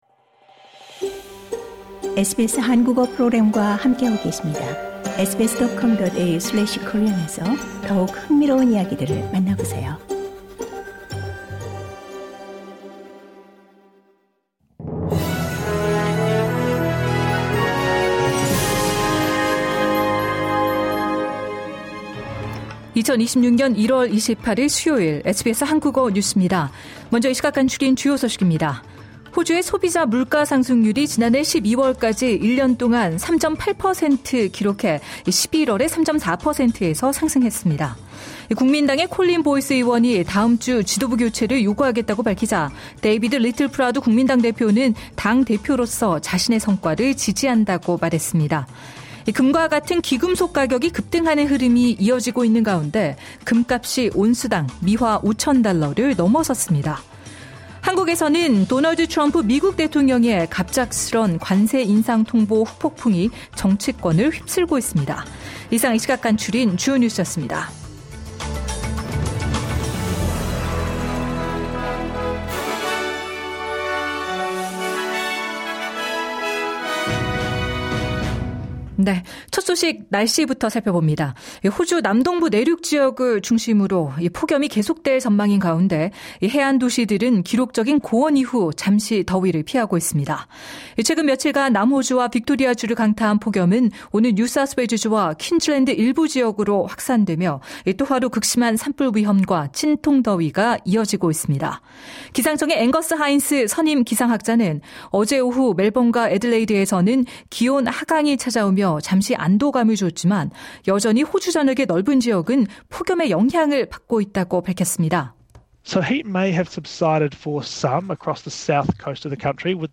지정학적 불안에 안전자산 쏠림 트럼프 ‘관세 인상 통보’ 후폭풍 한국 정치권 공방 확산 LISTEN TO 하루 10분 호주 뉴스: 1월 28일 수요일 SBS Korean 12:13 Korean 간추린 주요 뉴스 호주의 소비자물가상승률이 지난해 12월까지 1년 동안 3.8퍼센트를 기록해, 11월의 3.4퍼센트에서 상승했습니다.